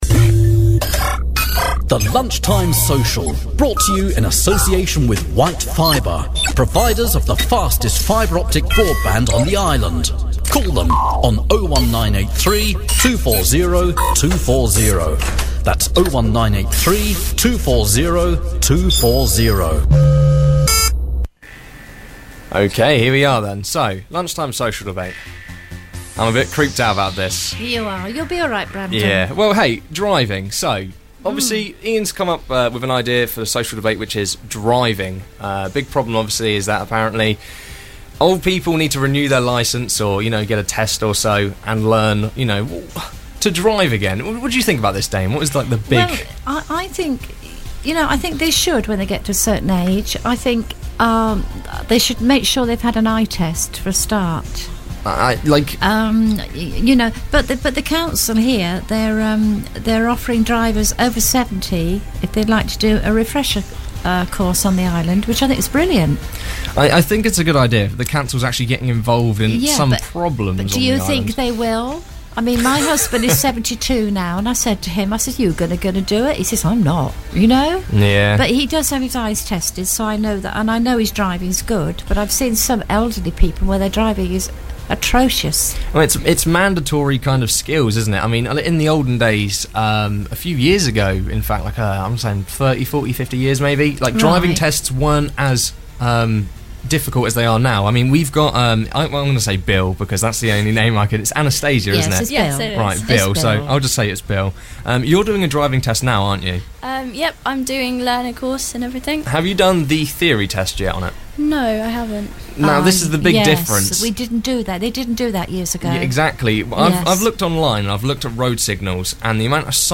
The Lunchtime Social Debate - Old Drivers, New Drivers. Island Drivers.